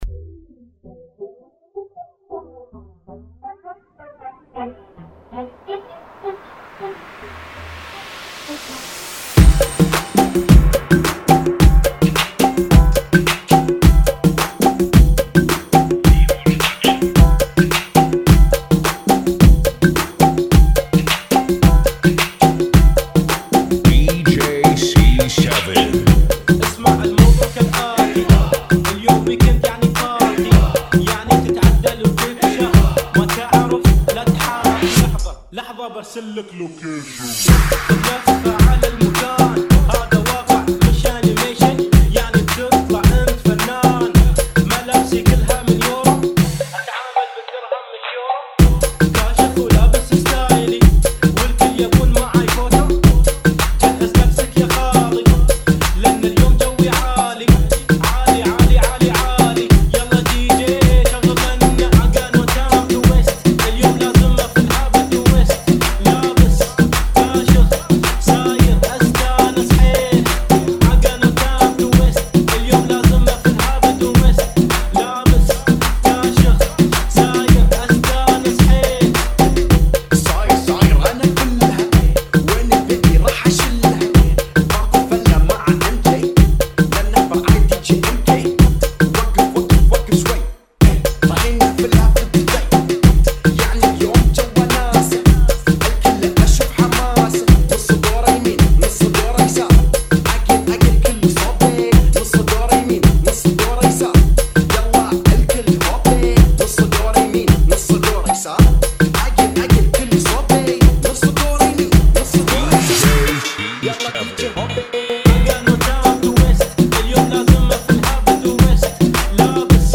BPM 108